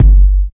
50 KICK.wav